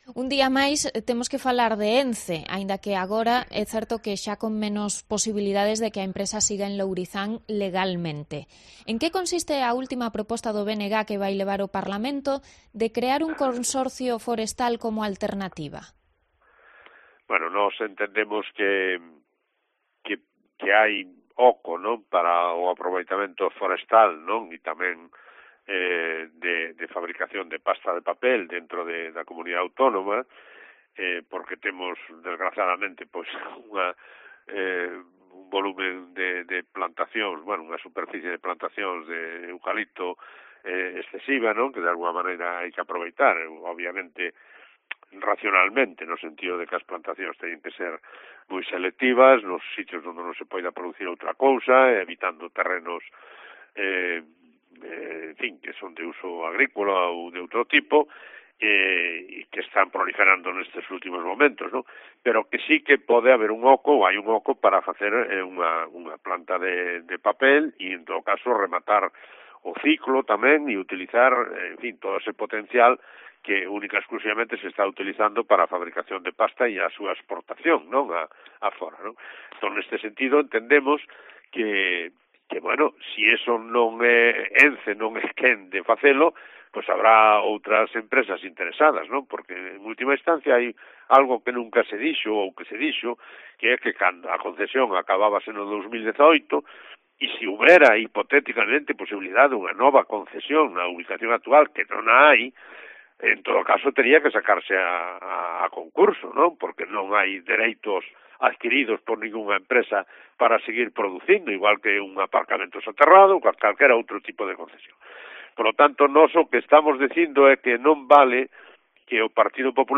Fragmento de la entrevista al alcalde de Pontevedra sobre el futuro de ENCE y sus trabajadores